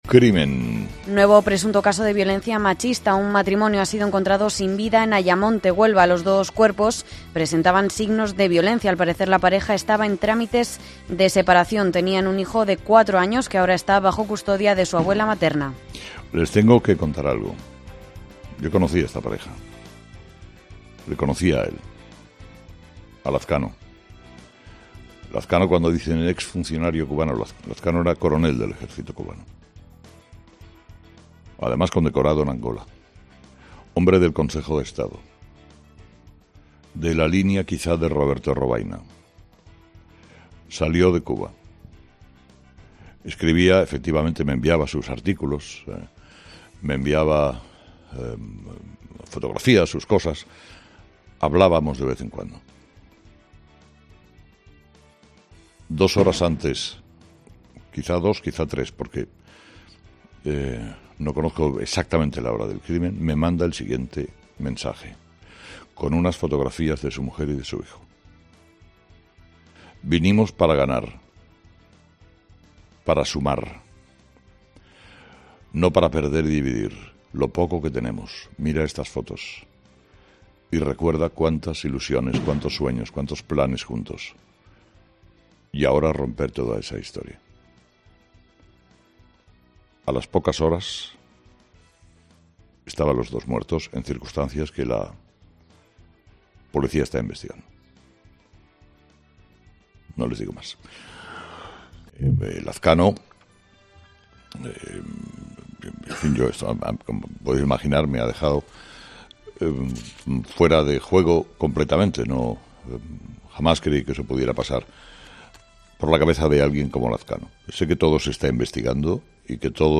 Carlos Herrera, justo después de su monólogo a las 8:10 horas de la mañana, ha interrumpido por un instante los titulares del día.